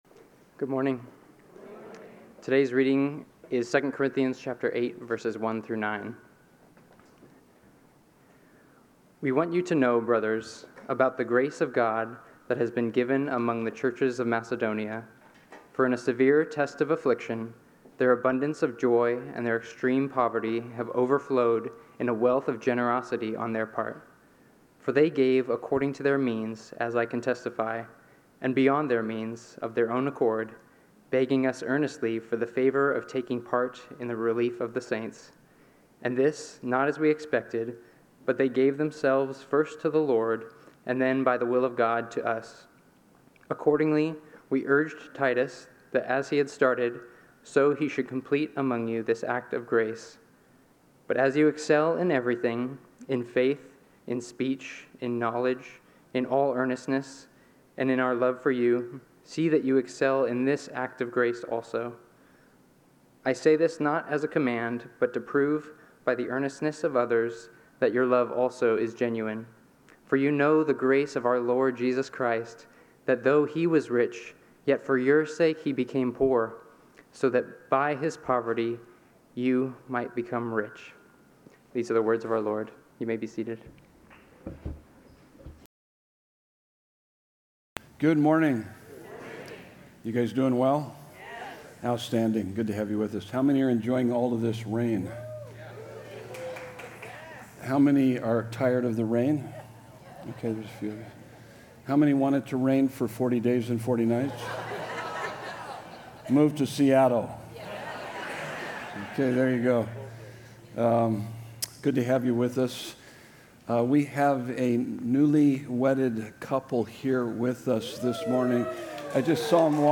Sermon Notes: Christ-Like Living